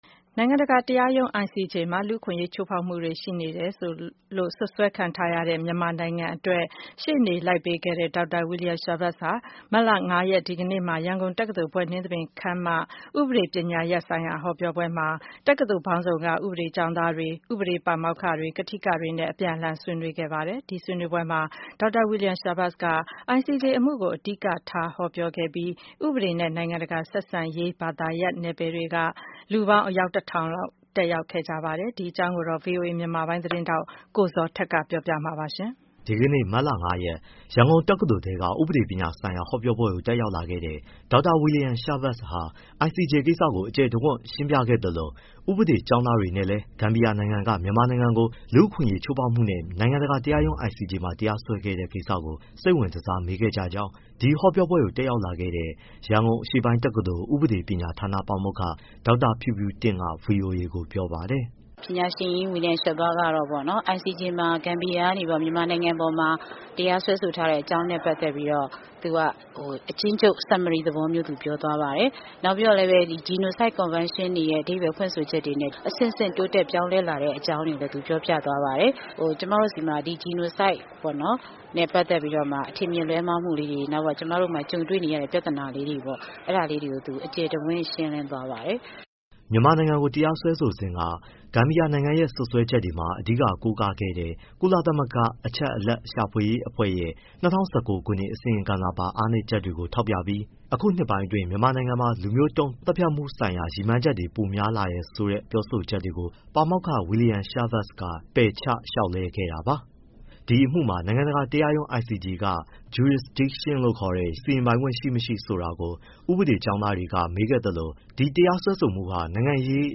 ရှေ့နေကြီး William Schabas ရန်ကုန်တက္ကသိုလ် ဥပဒေဌာနမှာ ဟောပြော
ဒေါက်တာ William Schabas ဟာ မတ်လ ၅ ရက်က ရန်ကုန်တက္ကသိုလ် ဘွဲ့နှင်းသဘင်ခန်းမမှာ လုပ်တဲ့ ဥပဒေပညာရပ်ဆိုင်ရာ ဟောပြောပွဲကို တက်ရောက်ပြီး တက္ကသိုလ်ပေါင်းစုံက ဥပဒေ ကျောင်းသားတွေ၊ ဥပဒေ ပါမောက္ခ၊ ကထိကတွေနဲ့ အပြန်အလှန် ဆွေးနွေးခဲ့ပါတယ်။